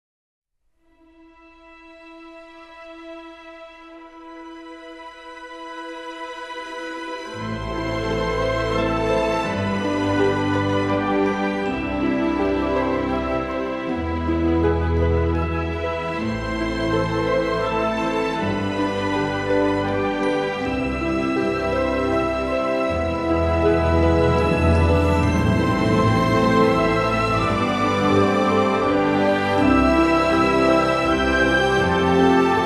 Arabic vocals and Shawm